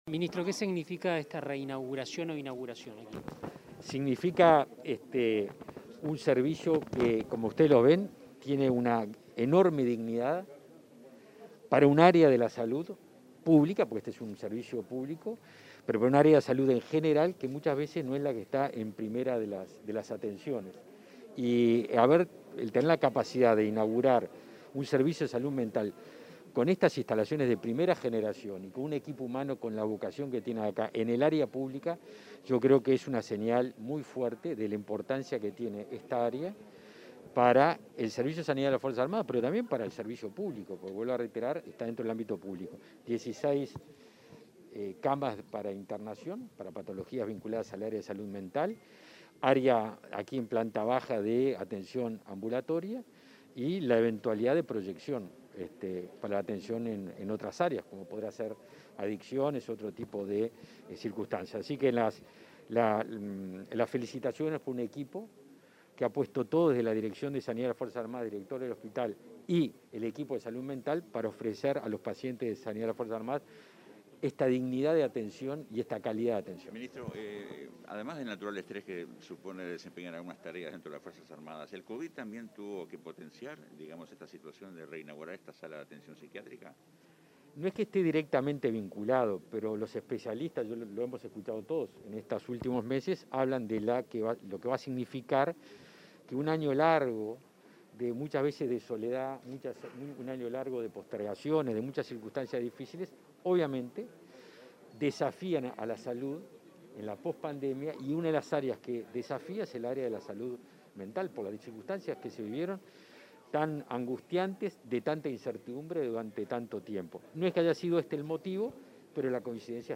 Declaraciones a la prensa del ministro de Defensa Nacional, Javier García
El ministro de Defensa Nacional, Javier García, participó, este martes 24, de la inauguración de la sala de internación de psiquiatría del Hospital